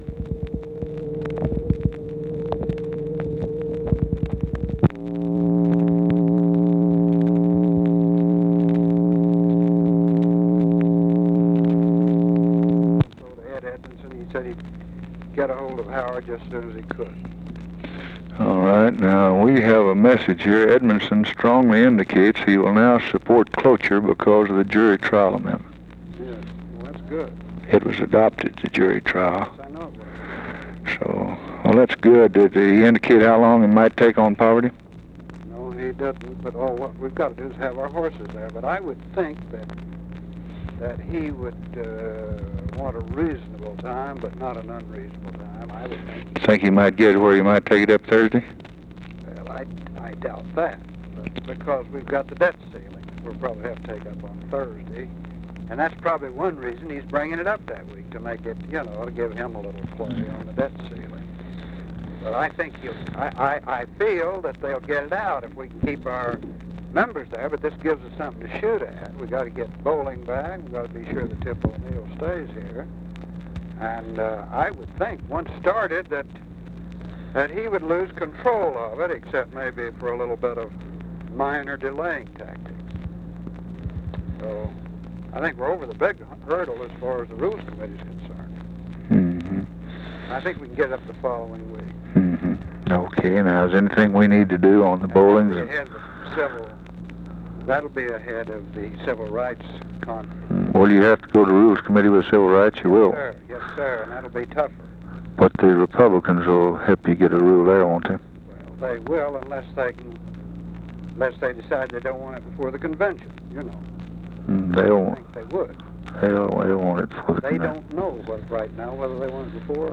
Conversation with CARL ALBERT, June 9, 1964
Secret White House Tapes